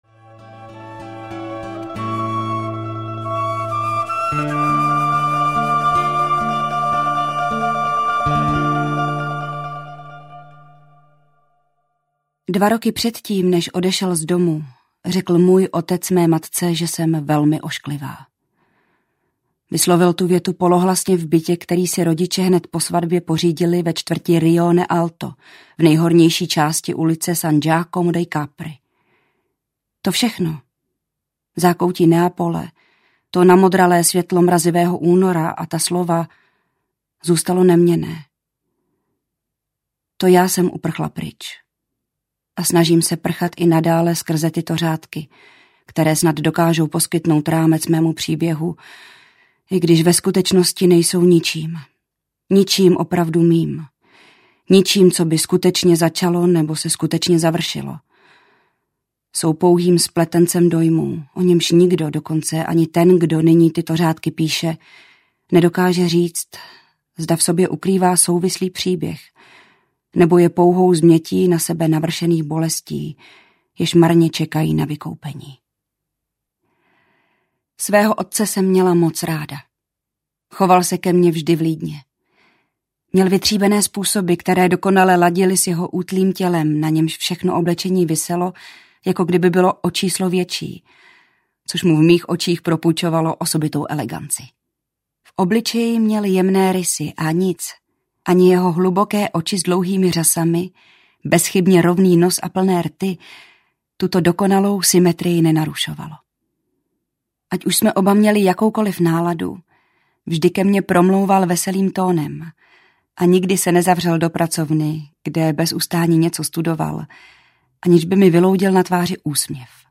Prolhaný život dospělých audiokniha
Ukázka z knihy